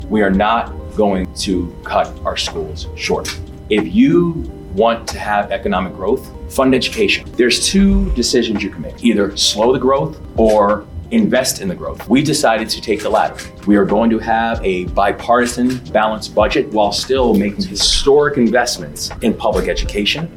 Maryland Governor Wes Moore visited with students in Frederick County recently, inviting them to ask questions.